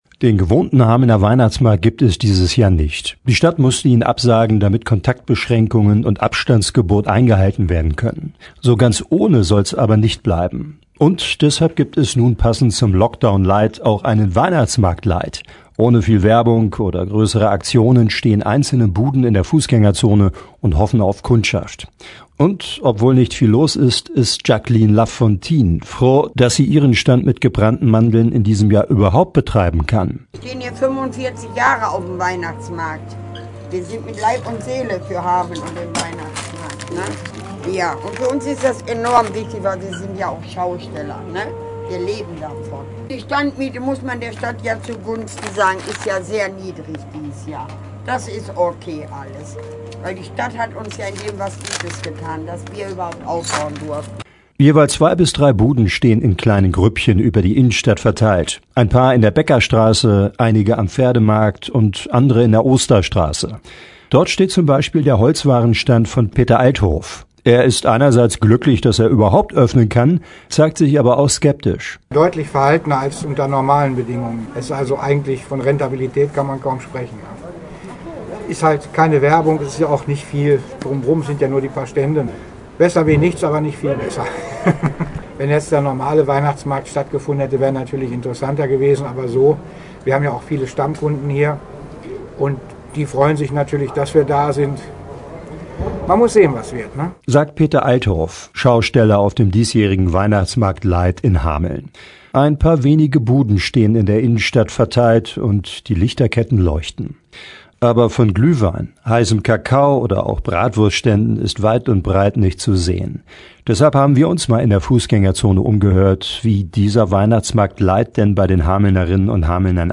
Jeweils 2-3 Buden stehen über die Innenstadt verteilt. Ein paar in der Bäckerstraße, einige am Pferdemarkt und andere in der Osterstraße. Wir haben uns umgehört, wie dieser „Weihnachtsmarkt light“ ankommt…